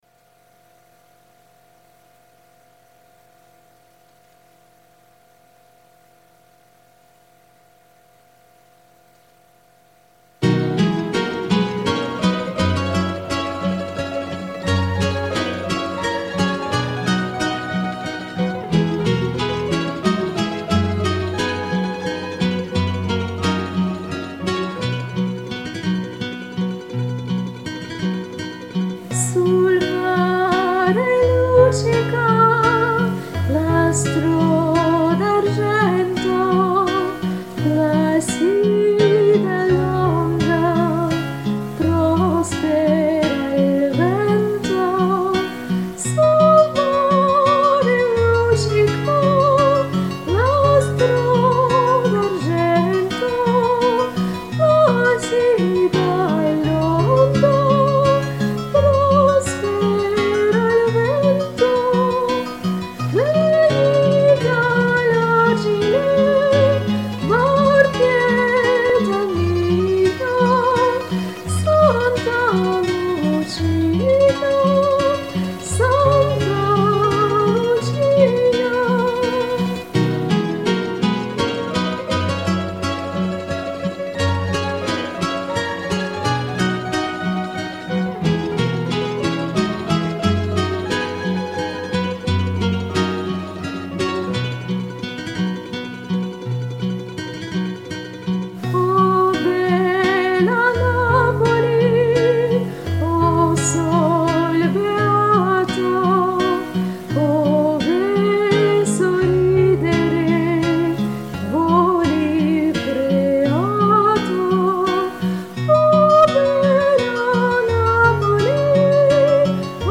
«Неаполитанские песни»
У Вас красивый вокал!!!